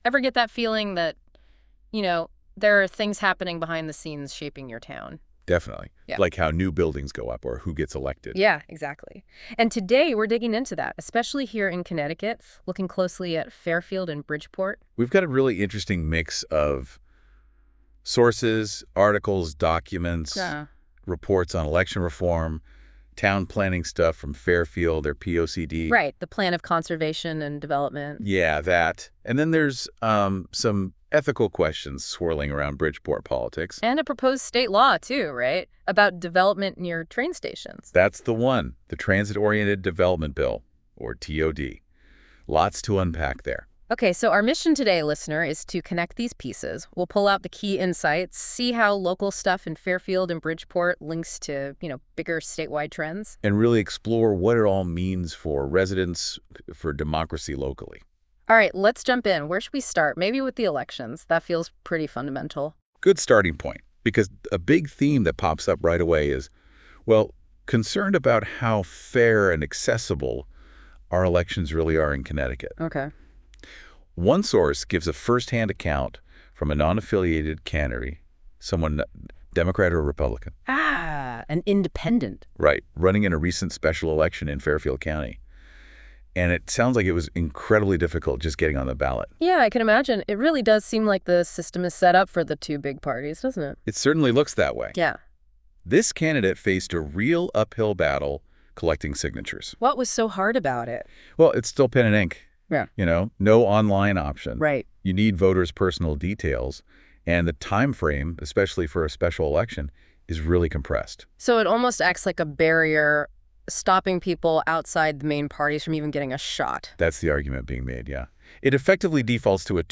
The Fixing of Connecticut interview 15′